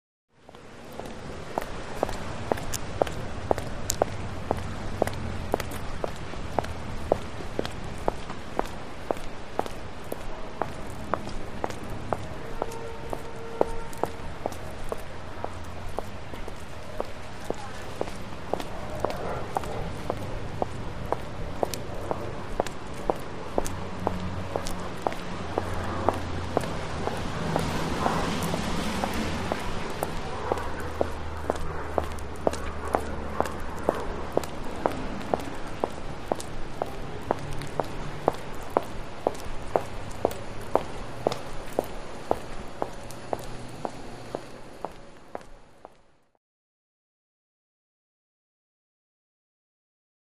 Close Single Footsteps, Boots On Sidewalk. Street Ambience, Wet Car By Medium, Distant Plane Overhead.